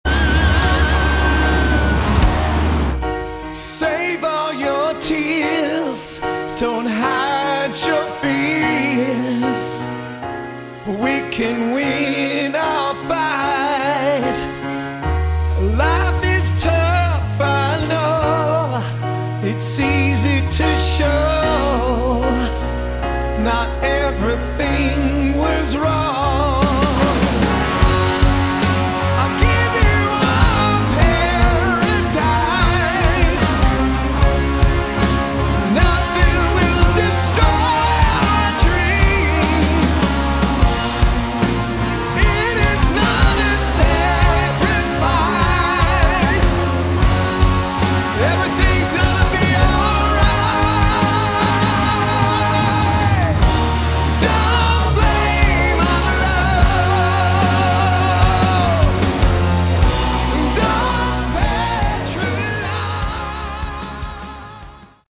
Category: Hard Rock
Vocals
Guitars, keyboards, bass
Drums